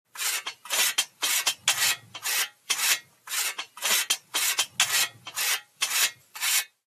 На этой странице собраны звуки косы — от металлического звона лезвия до ритмичного шелеста скошенной травы.
Звук ручной заточки косы